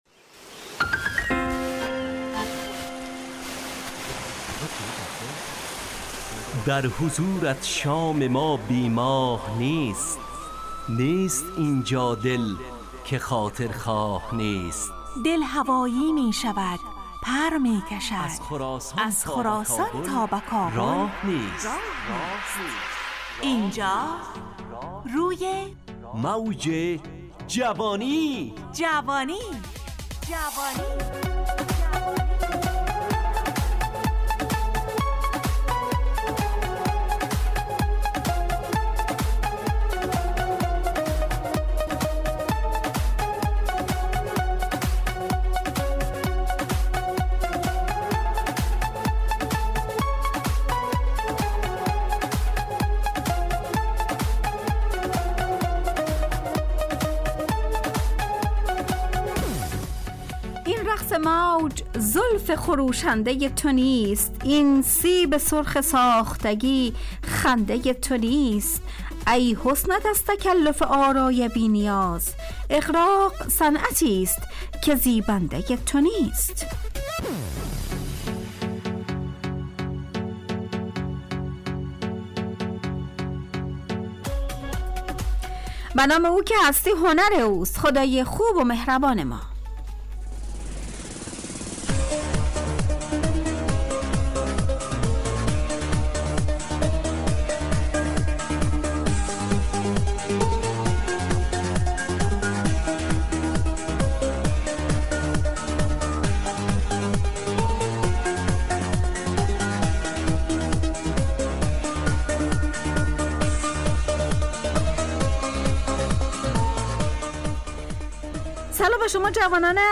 همراه با ترانه و موسیقی مدت برنامه 70 دقیقه . بحث محوری این هفته (دغدغه)
روی موج جوانی برنامه ای عصرانه و شاد